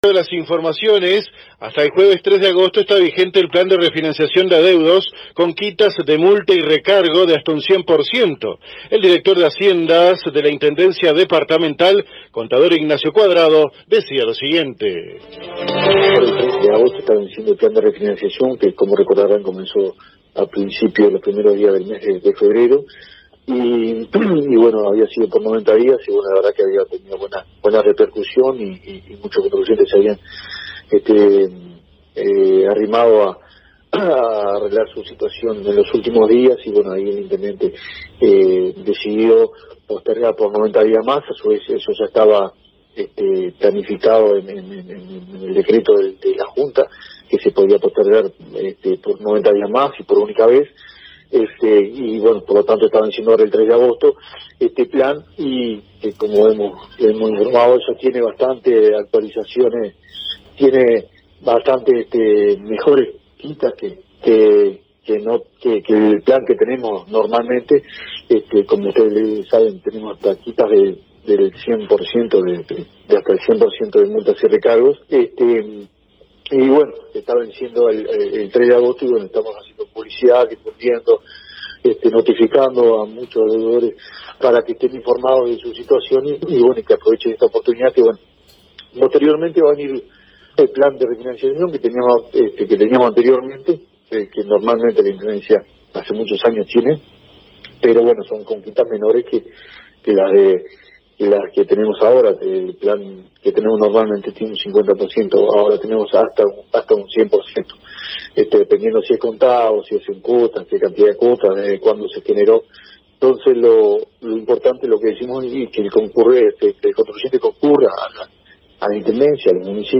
Así lo informó el Contador Ignacio Cuadrado, Director de esta dependencia, en contacto con la AM 1110 local, agregando que dicho plan comenzó en el mes de febrero y estuvo vigente por 90 días, sin embargo, se autorizó una sola extensión de 90 días adicionales, lo cual estaba contemplado en el proyecto presentado ante la Junta Departamental.
Fuente: AM 1110 - Paso de los Toros